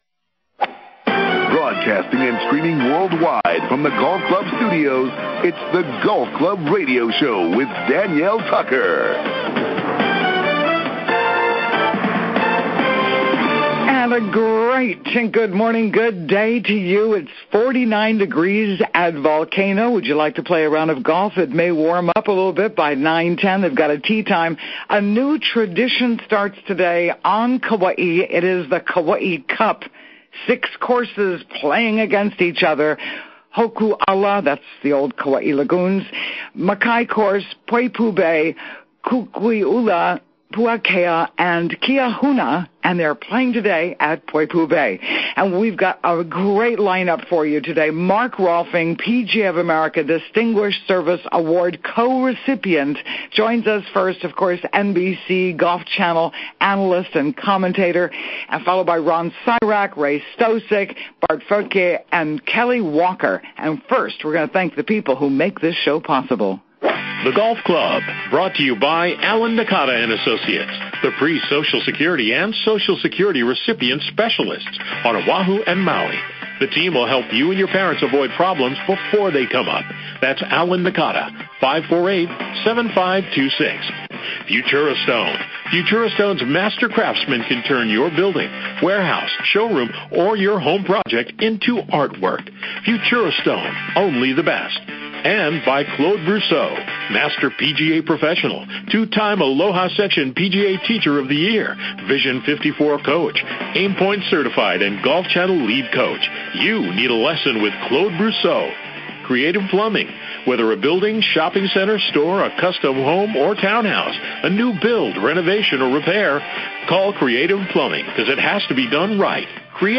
Mark Rolfing NBC Golf Channel Analyst and Commentator